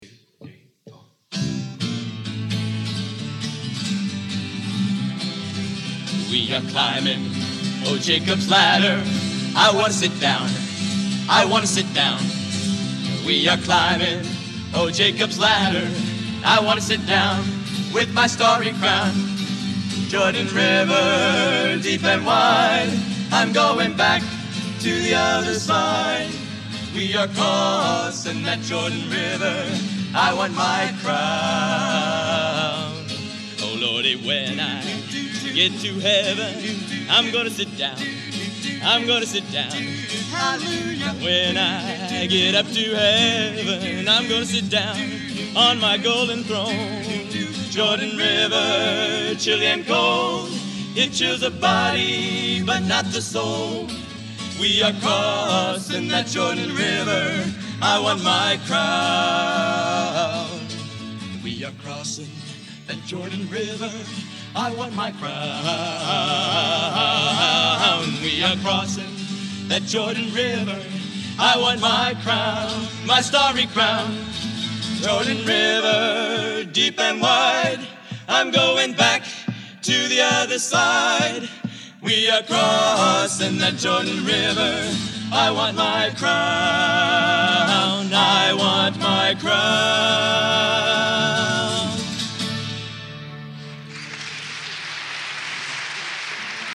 Genre: Sacred Spiritual | Type: Featuring Hall of Famer
Raucous Trio